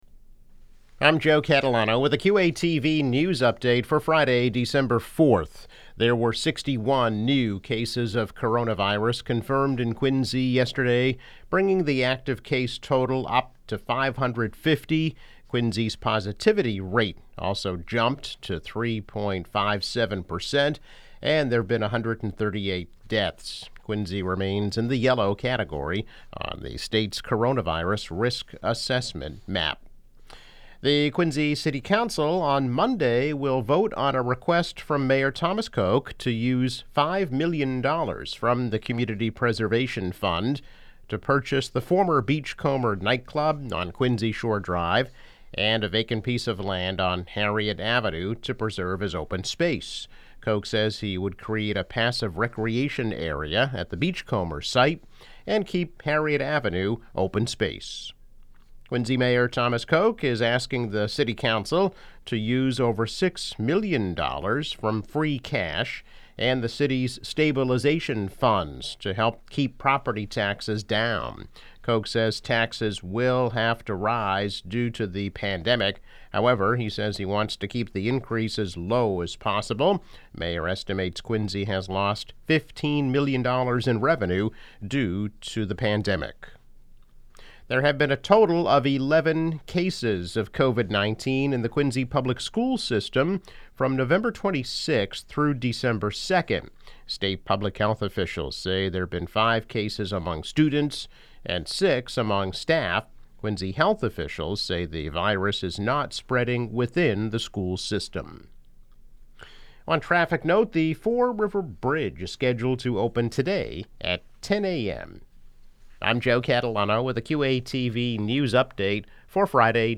News Update - December 4, 2020